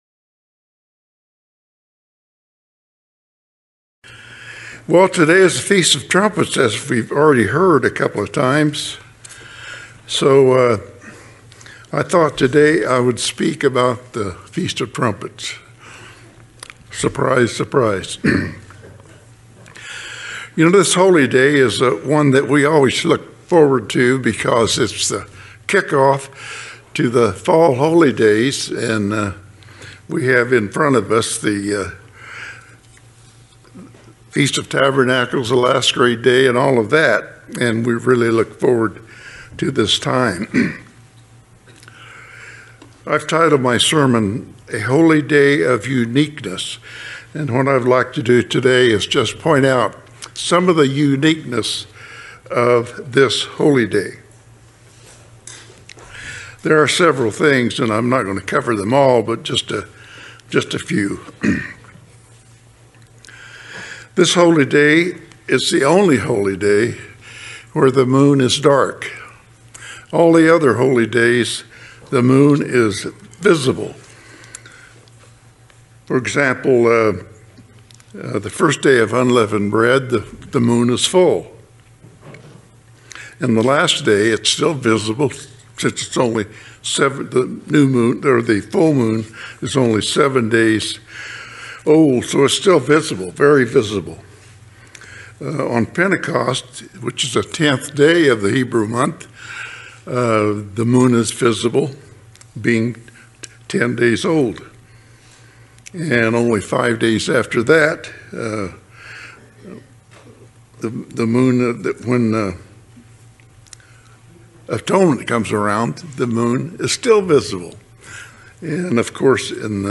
Sermons
Given in Las Vegas, NV Redlands, CA San Diego, CA